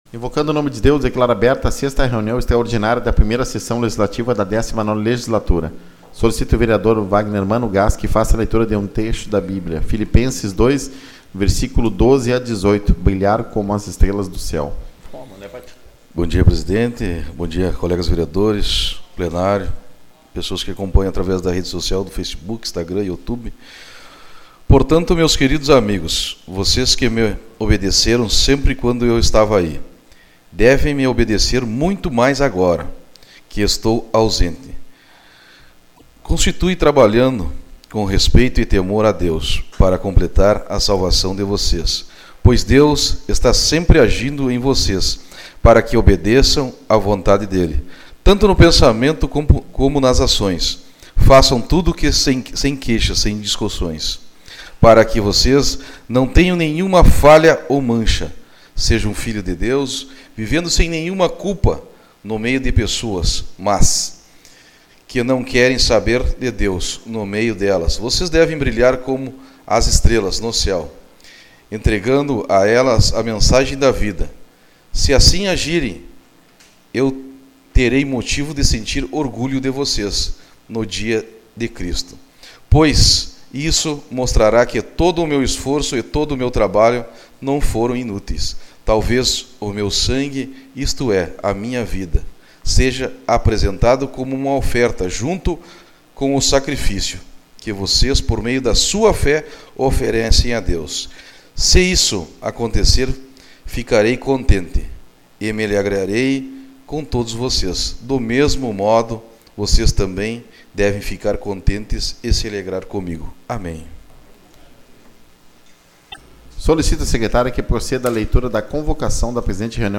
Resumo (6ª Extraordinária da 1ª Sessão Legislativa da 19ª Legislatura)
Tipo de Sessão: Extraordinária